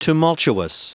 Prononciation du mot tumultuous en anglais (fichier audio)
Prononciation du mot : tumultuous